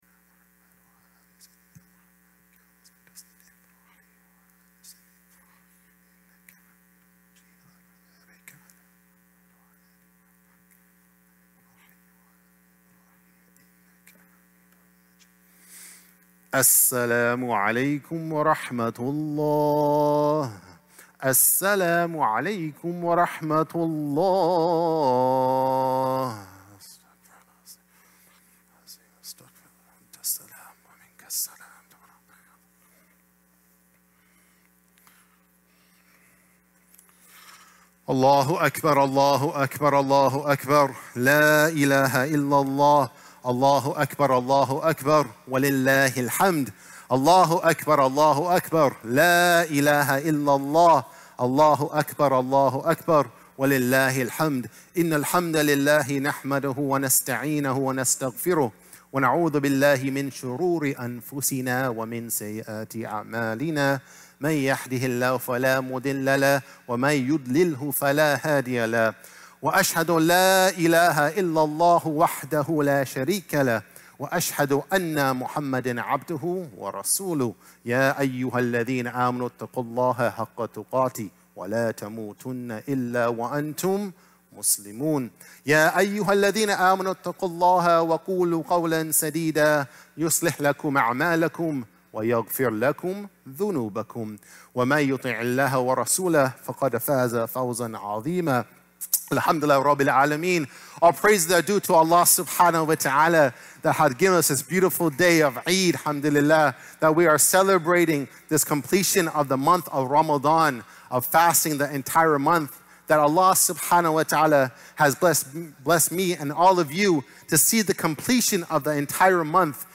First Eid-ul-Fitr Prayer Khutbah